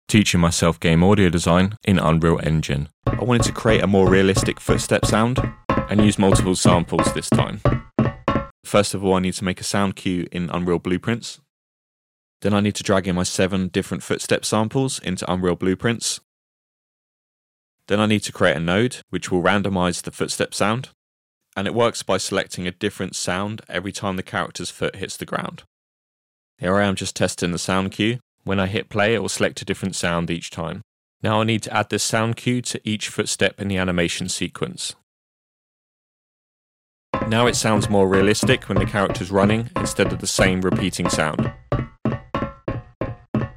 # 4 Teaching myself game audio design in Unreal Engine. Realistic footsteps on metal.